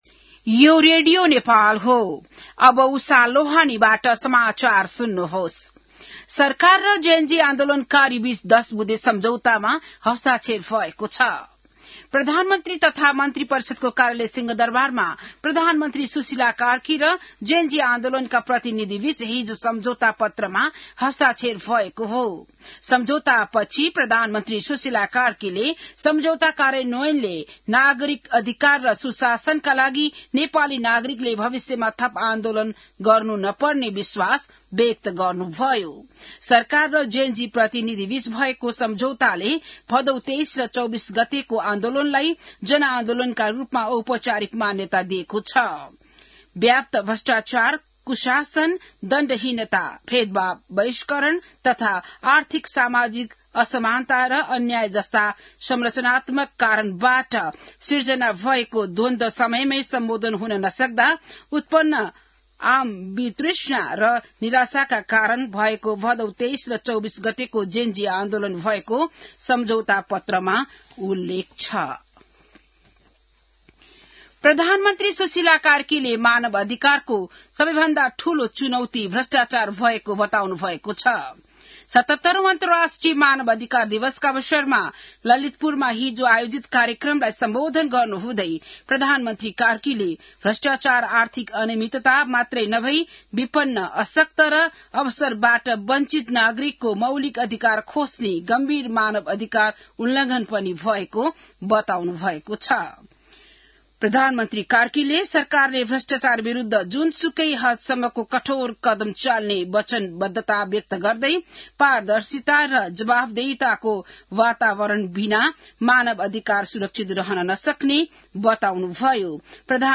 बिहान १० बजेको नेपाली समाचार : २५ मंसिर , २०८२